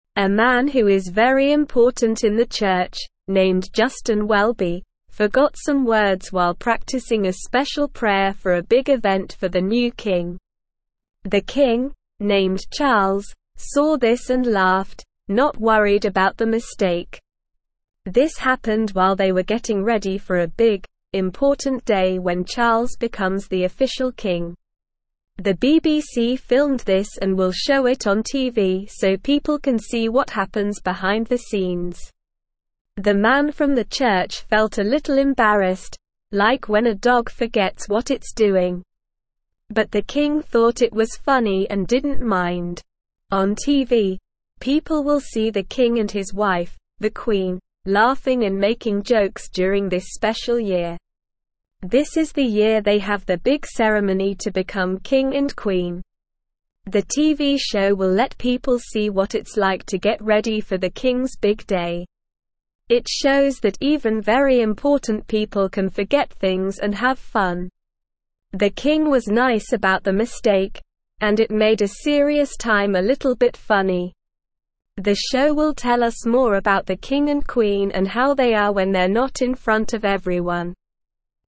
Slow
English-Newsroom-Lower-Intermediate-SLOW-Reading-The-Kings-Funny-Video-A-Special-Look.mp3